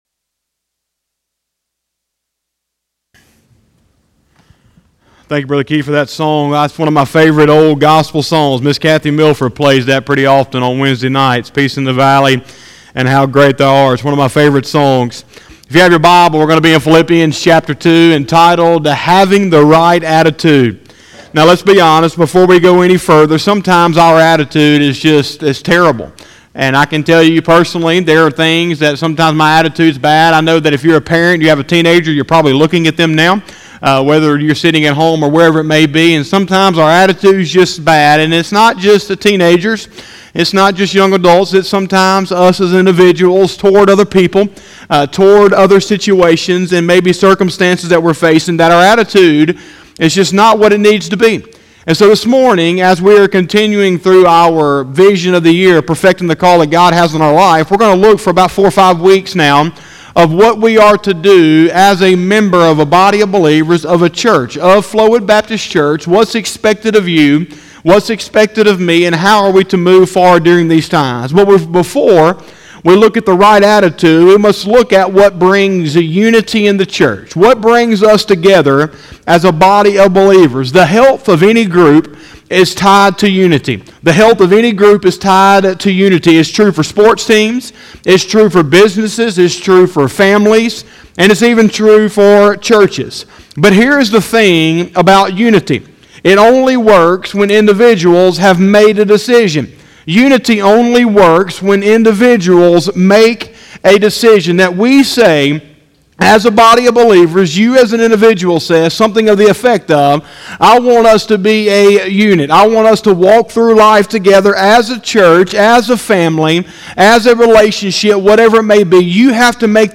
05/03/2020 – Sunday Morning Service